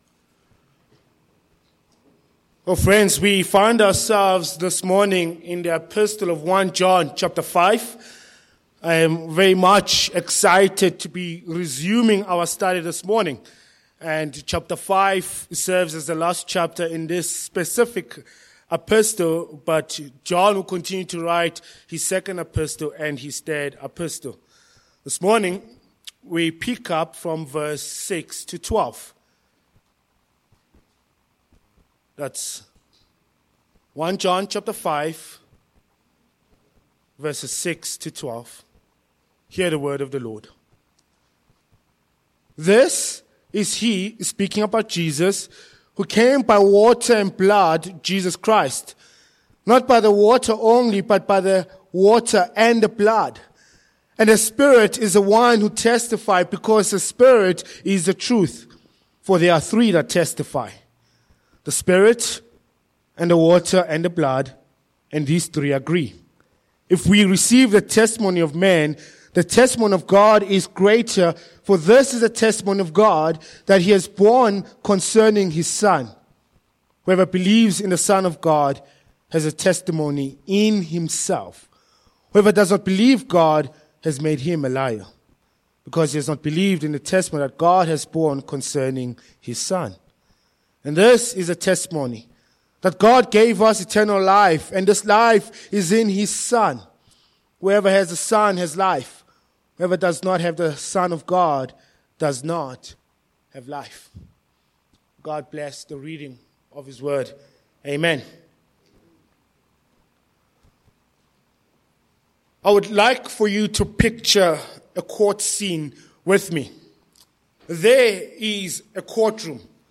Sermon points: 1. The Three Witnesses v6-8 a. The Witness of Baptism b. The Witness of Crucifixion c. The Witness of the Spirit
1 John 5:6-12 Service Type: Morning Passage